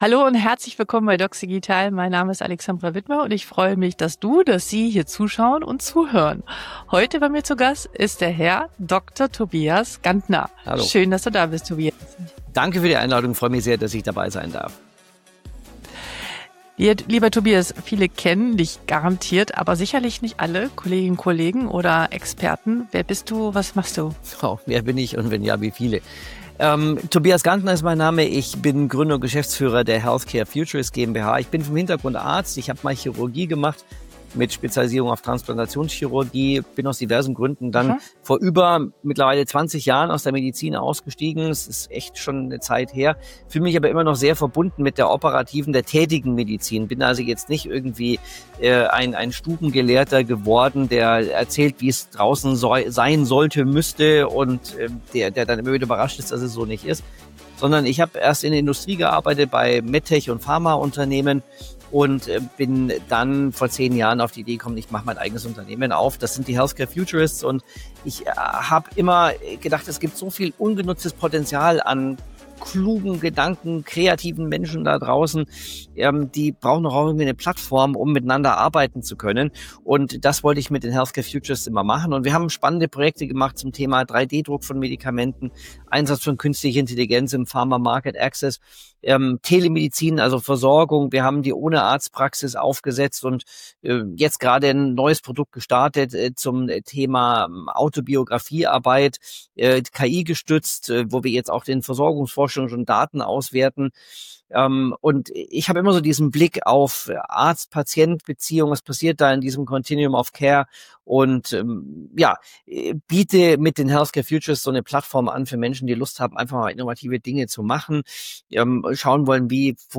Die Zukunft der Medizin hat schon begonnen - Ein Gespräch